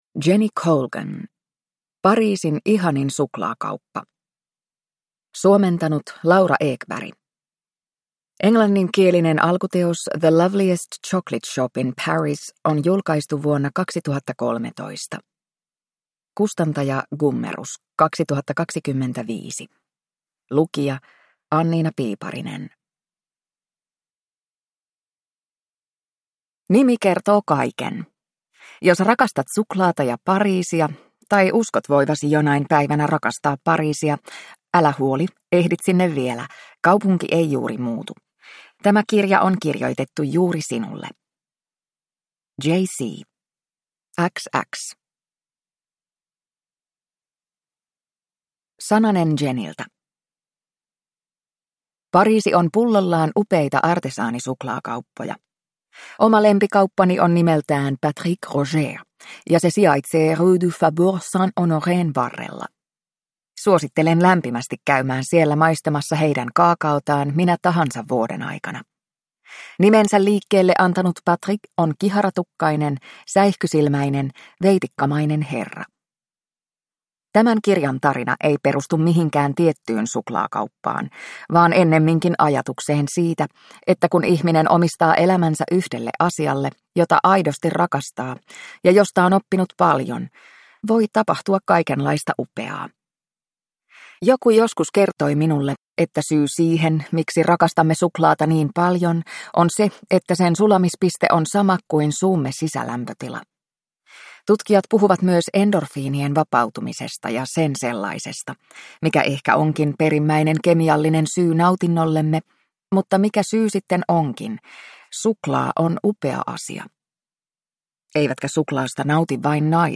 Pariisin ihanin suklaakauppa – Ljudbok